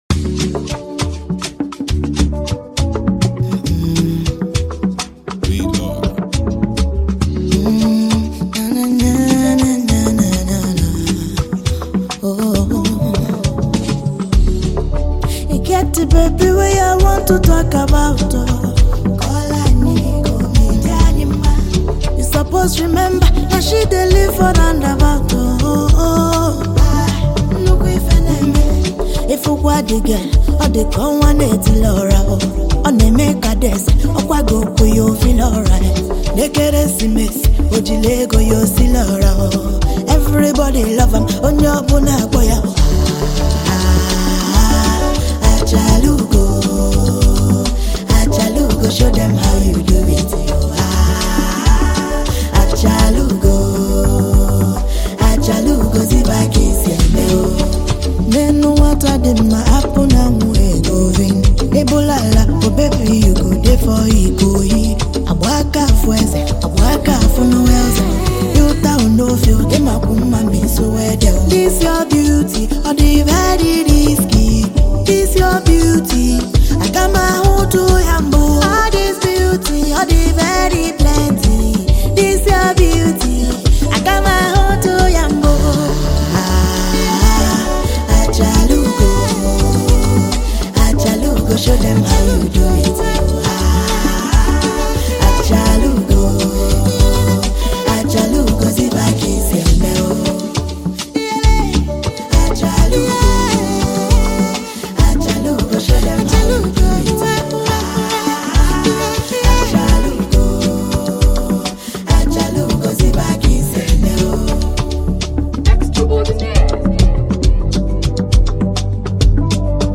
Afro-Beat
a distinctive Afro-pop sound